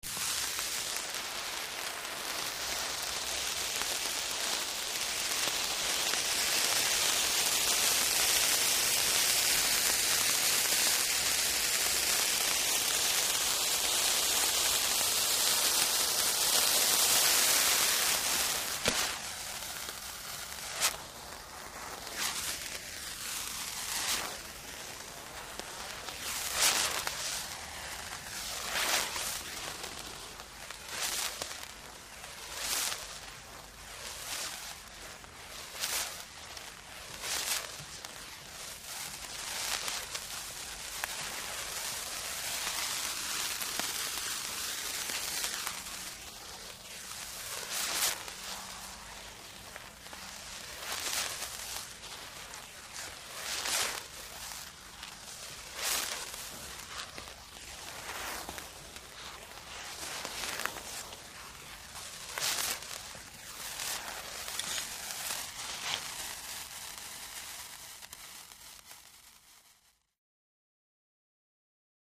Fireworks Sparkler Sizzle, Cu Perspective, W Torch Bys.